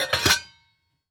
metal_lid_movement_impact_07.wav